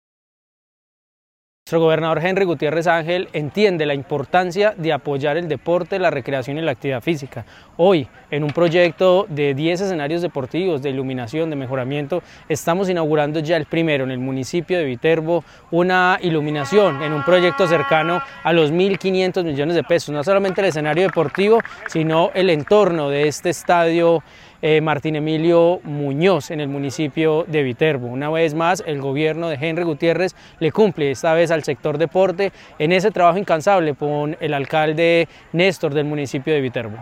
Ronald Bonilla, gobernador (E) de Caldas.
ronal-bonilla-gobernador-encargado-de-Caldas.mp3